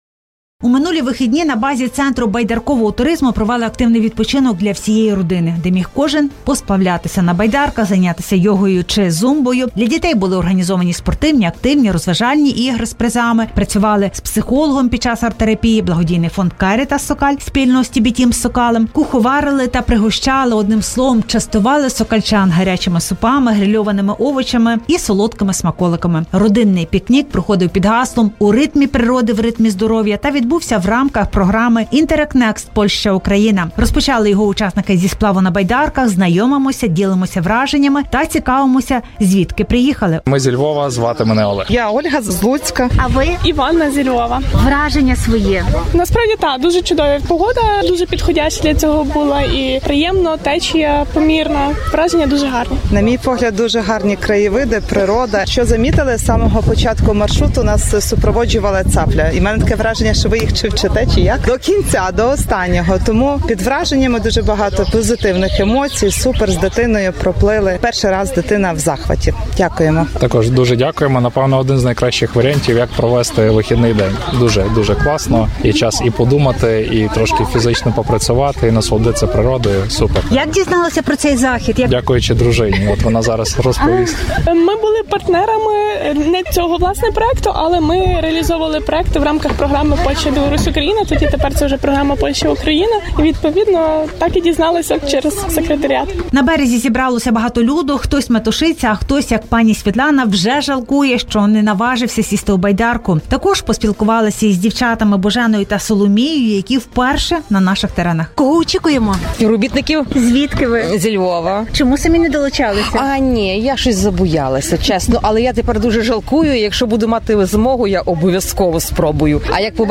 Радіопрограми та матеріали ЗМІ в Польщі